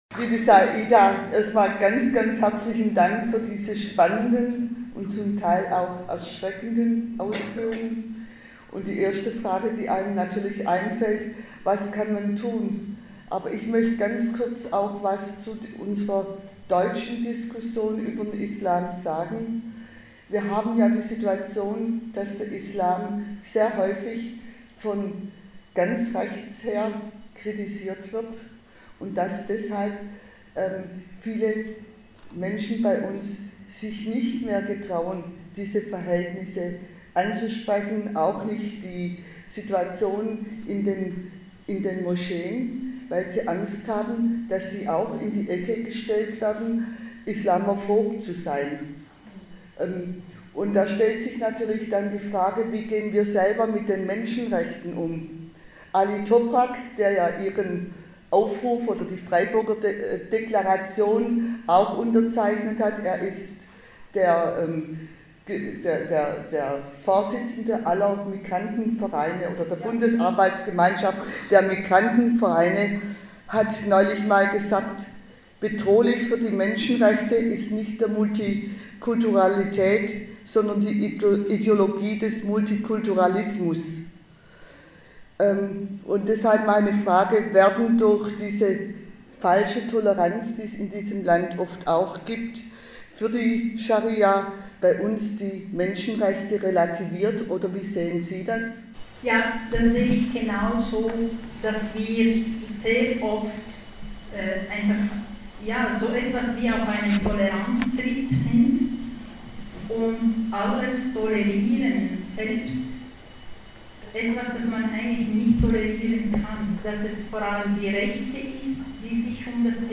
Der Vortrag fand in einem Veranstaltungssaal der Uhlandmensa statt.